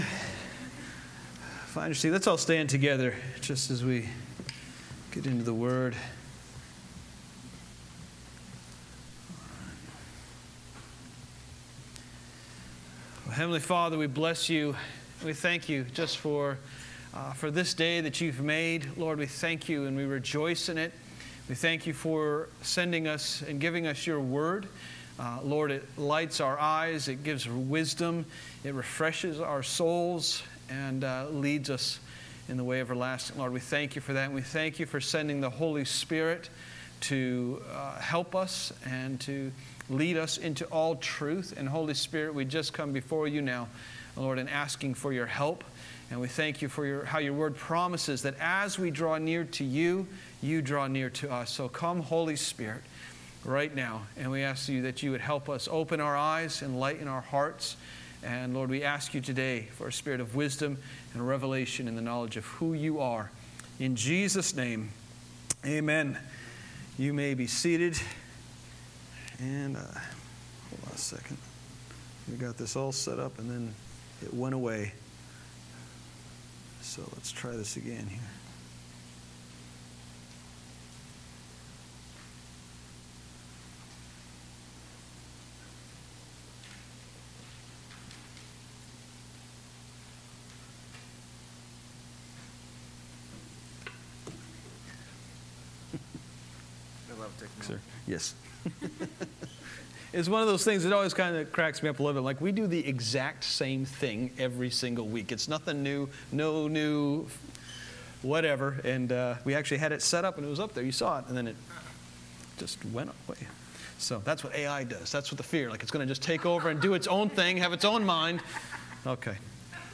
Matthew 13:47-52 Gospel according to Matthew Preached by